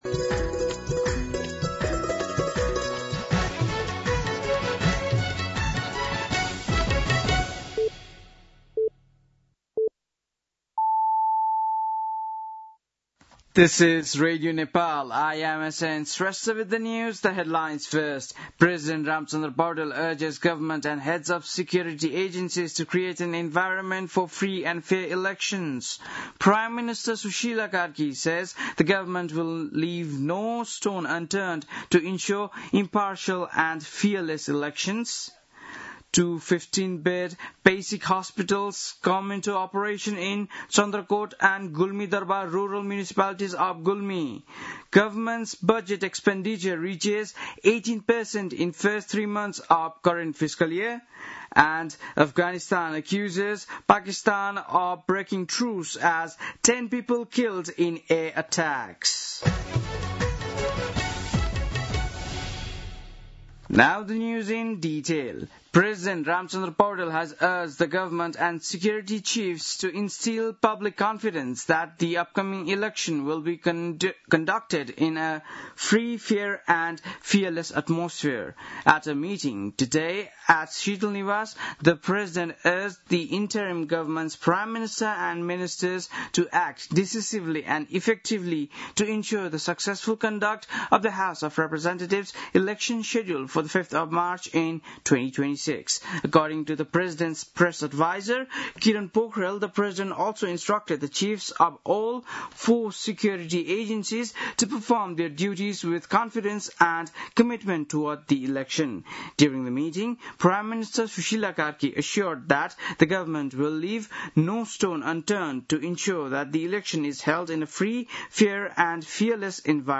बेलुकी ८ बजेको अङ्ग्रेजी समाचार : १ कार्तिक , २०८२